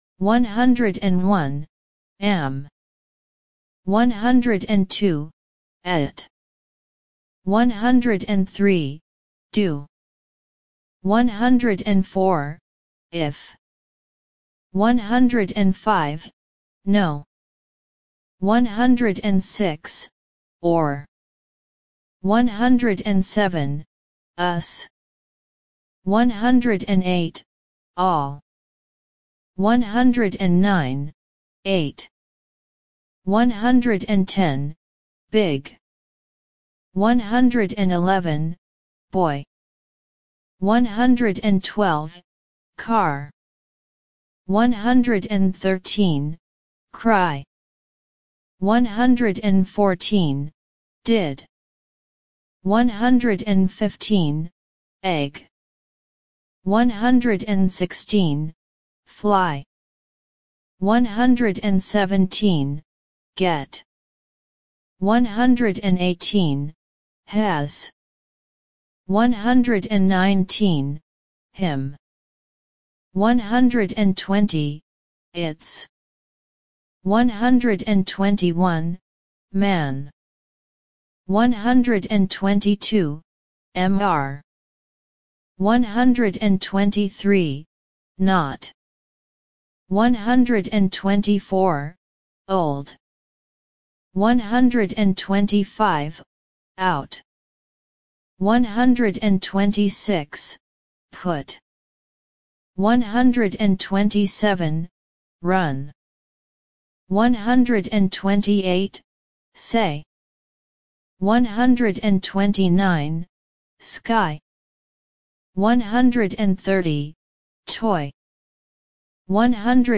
Listen and Repeat.
101 – 200 Listen and Repeat